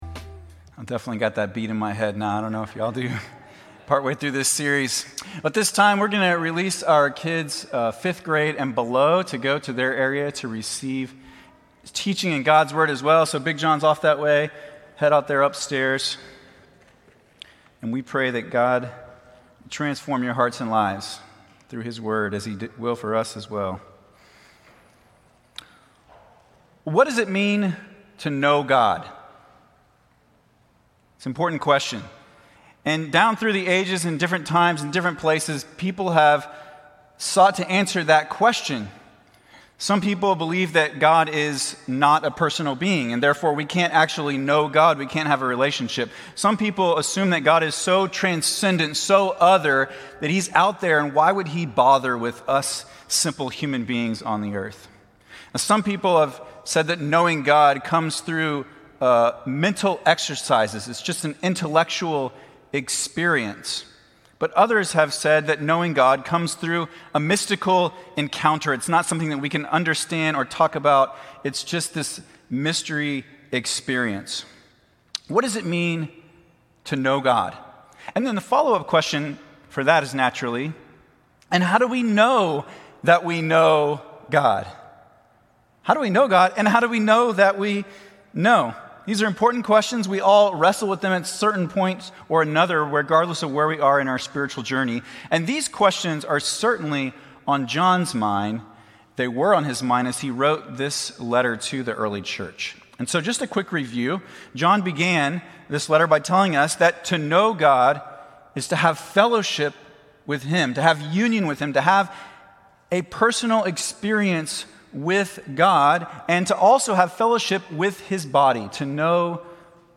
A message from the series "Uniquely United."